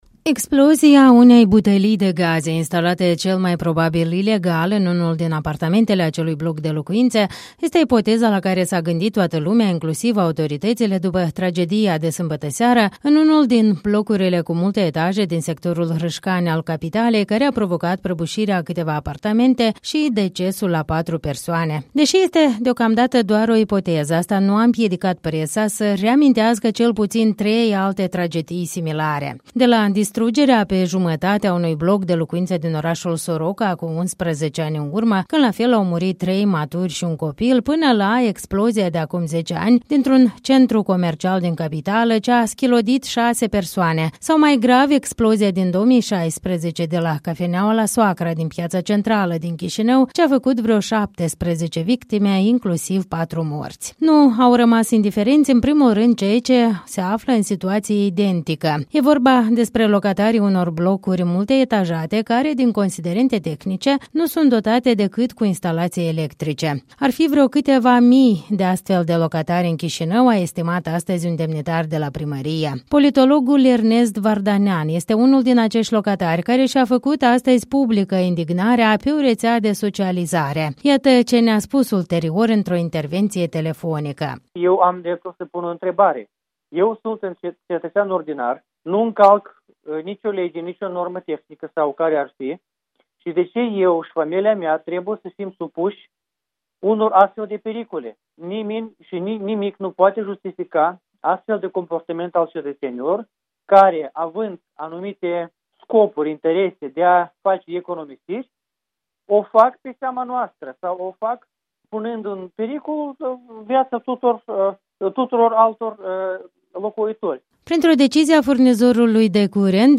Iată ce ne-a spus ulterior într-o intervenţie telefonică: